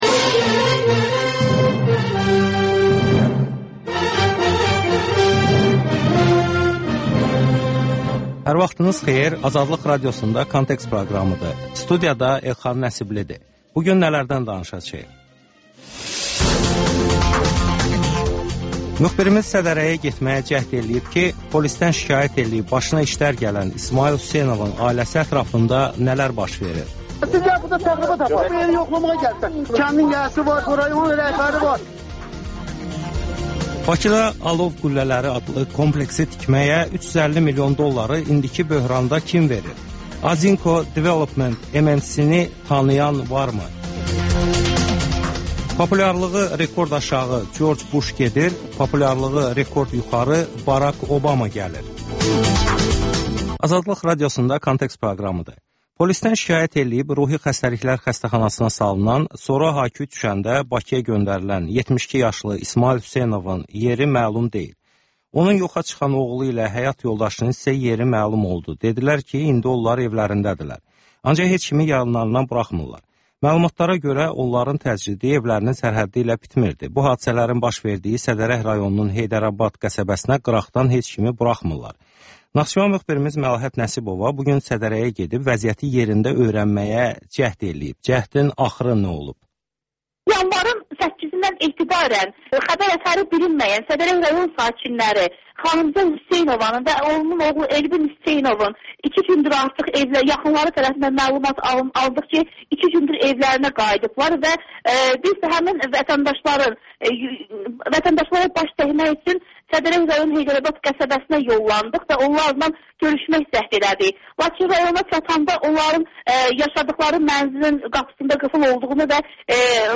Müsahibələr, hadisələrin müzakirəsi, təhlillər (Təkrar)